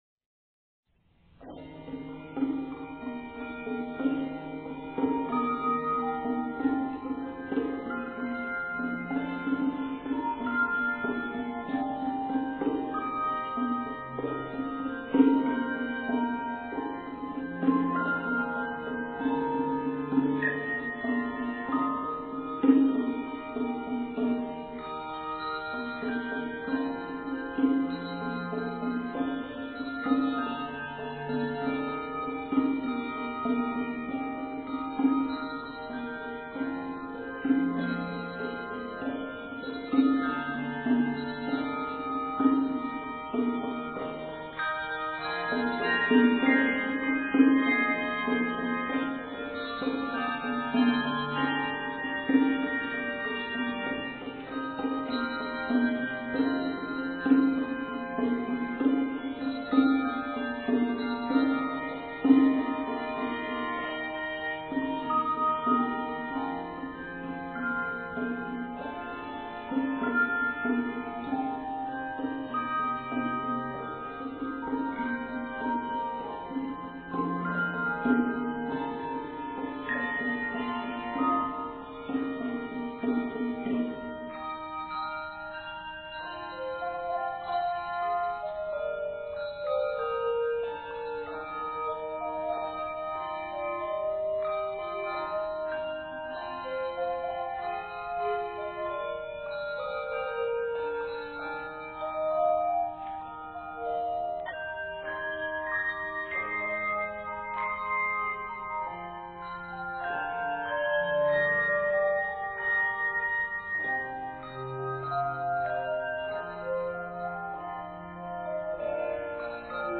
lively arrangement